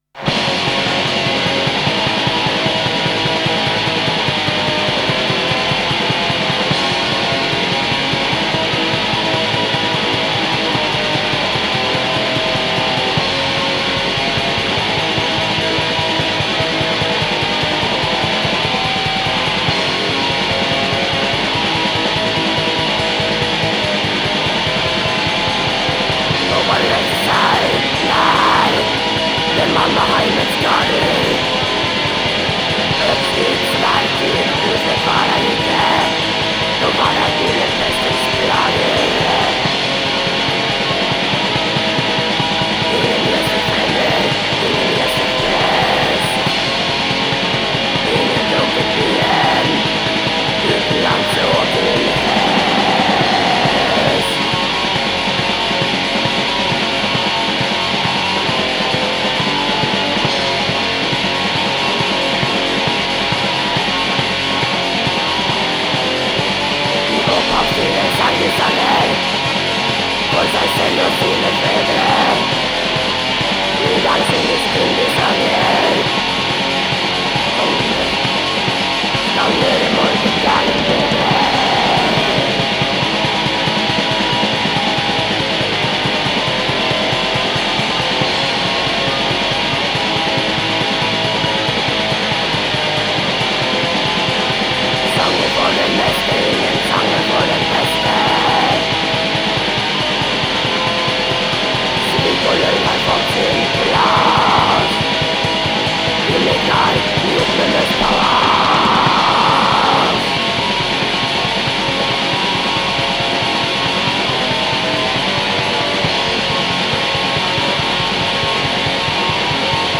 یک البوم بسیار تاریک و خشن و اتمسفریک
بلک متال نروژی